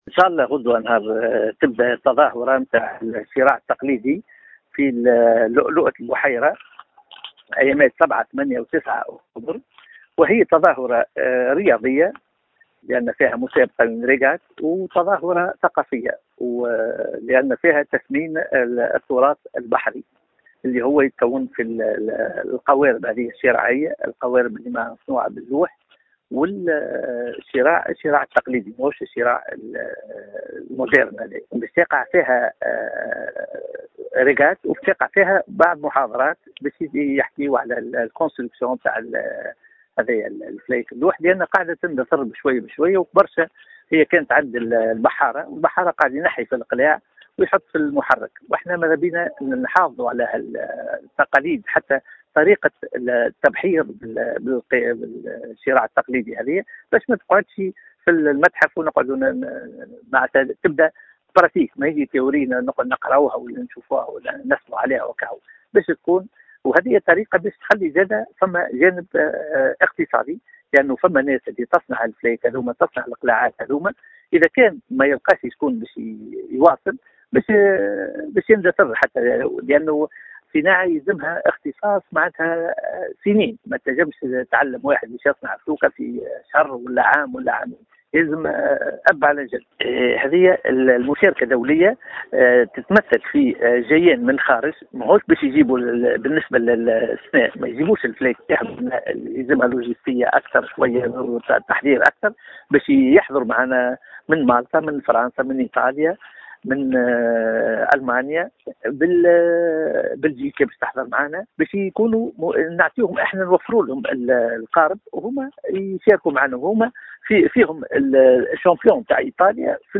وفي تصريح للجوهرة أف أم